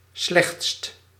Ääntäminen
US : IPA : /ˈwɝst/